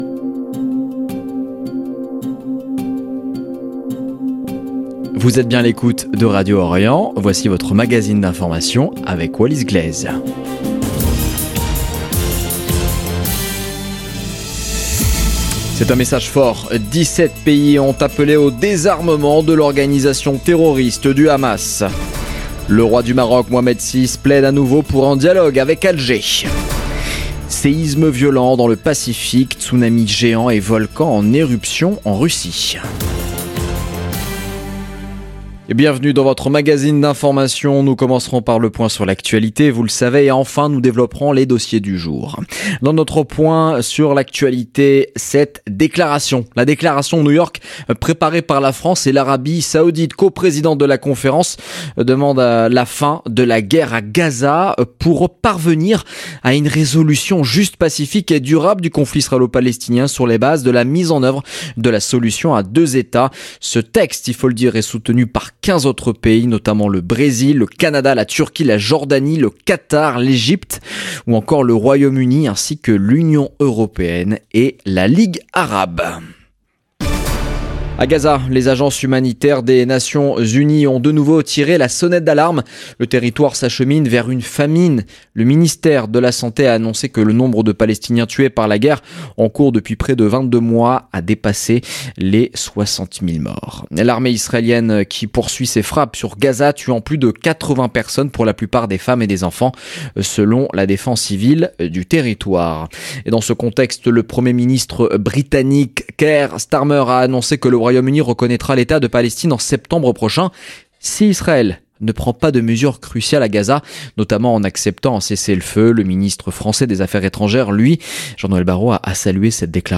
Magazine d'information du mercredi 30 juillet 2025